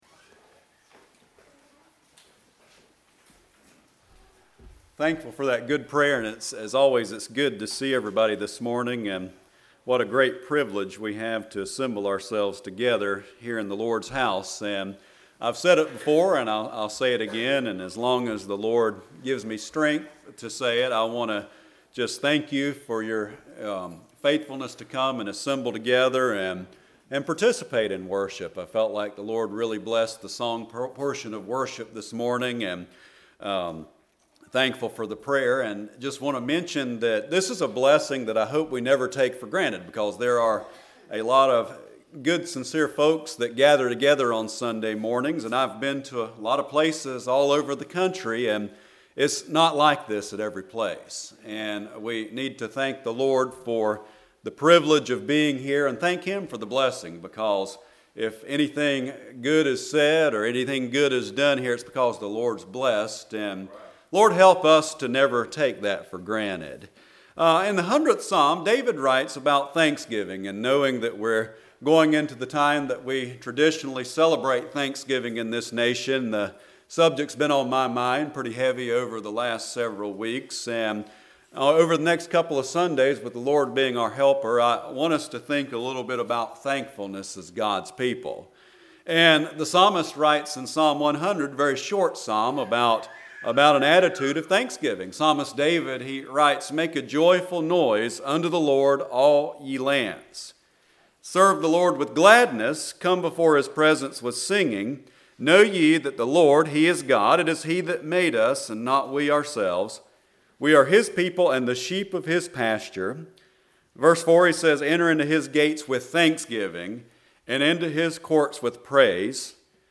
11/03/19 Sunday Morning
Passage: Psalms 100 Service Type: Sunday Morning